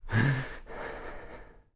Player_UI [20].wav